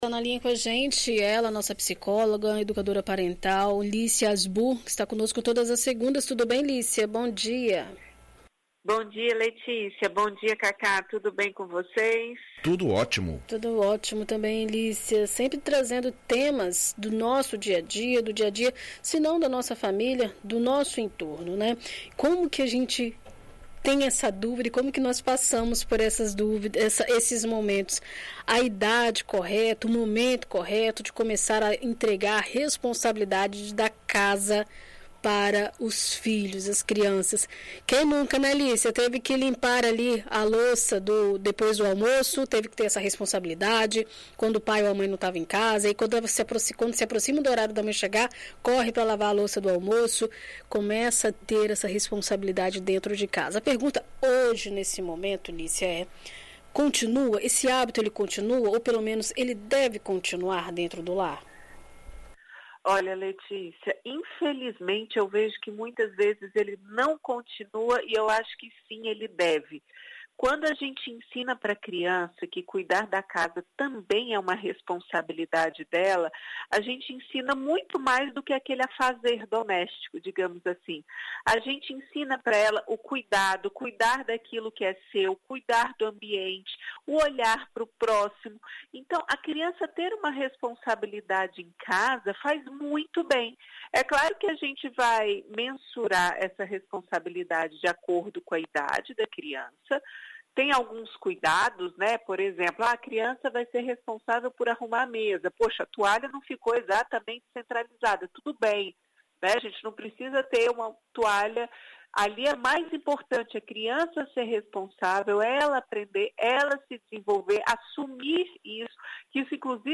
Em entrevista a BandNews FM Espírito Santo nesta segunda-feira